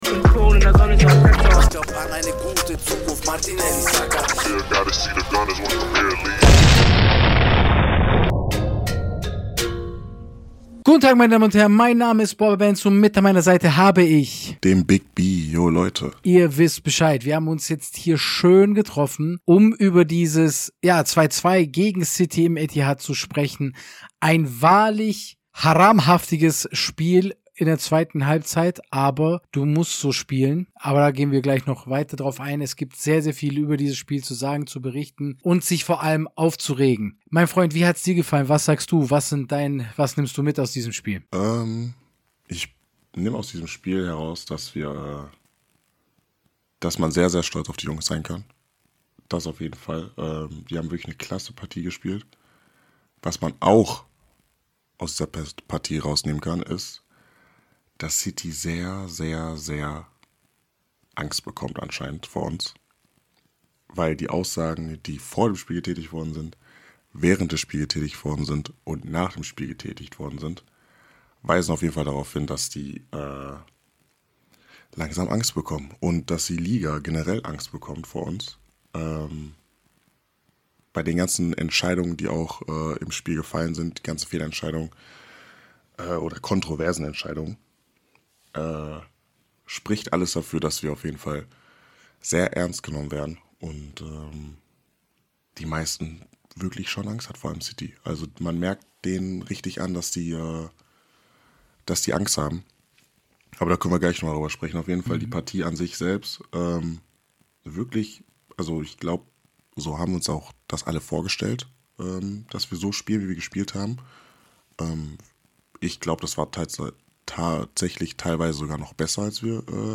Stolz auf die Mannschaft und wütend auf die selbstdarstellerischen Schiris und deren Verband. Sorry for the Ragemodus.